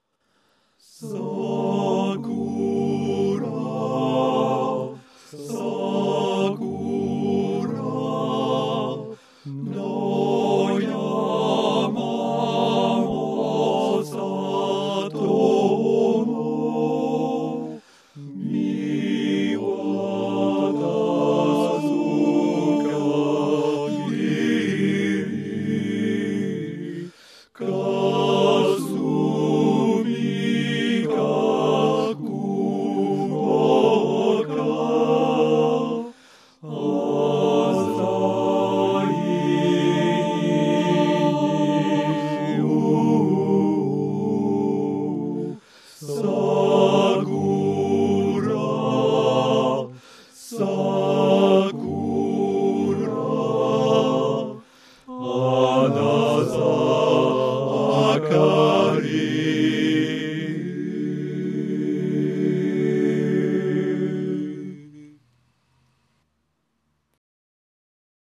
Traditionnel japonais
( les 4 voix ensemble )